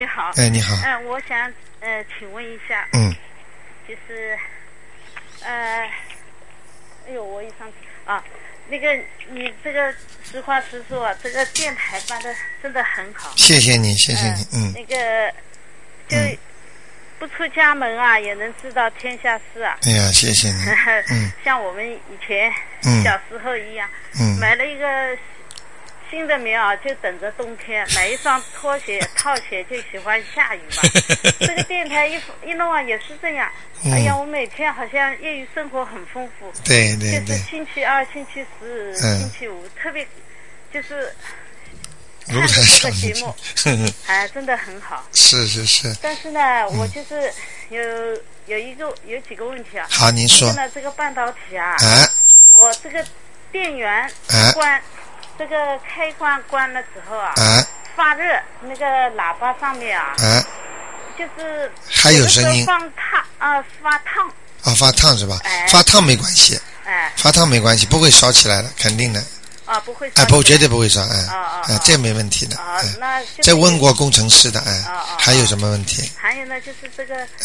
目录：☞ 剪辑电台节目录音_集锦